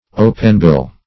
Openbill \O"pen*bill`\, n. (Zool.)